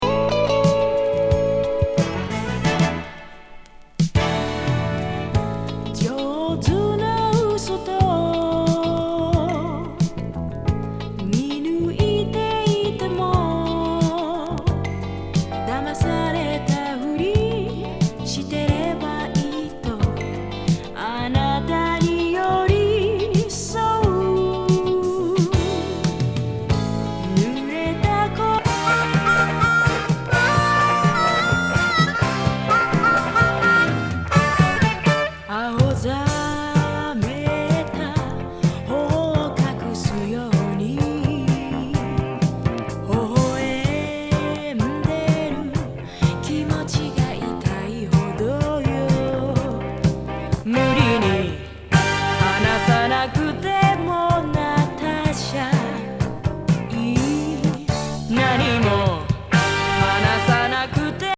込み上げソウル
ディスコ